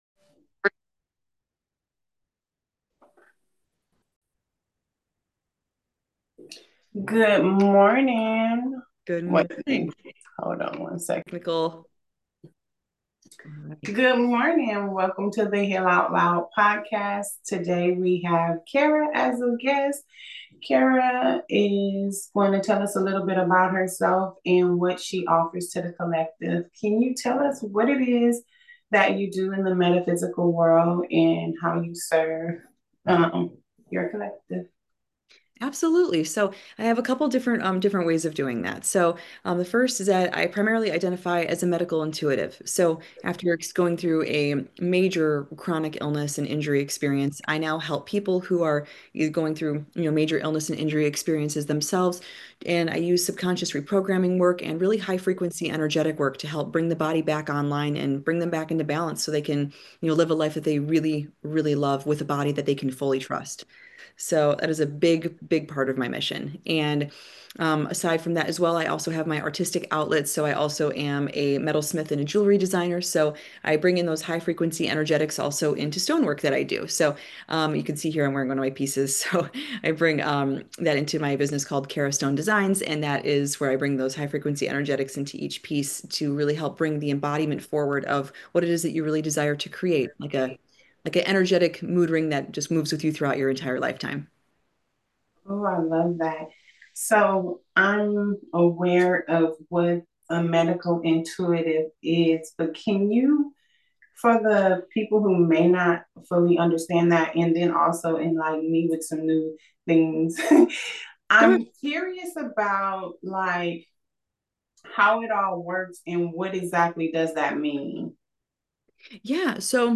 1 Ep. 25 - A Conversation with Neale Donald Walsch: God Talks to Everyone 59:20